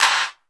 Southside Clapz (19).WAV